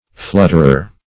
\Flut"ter*er\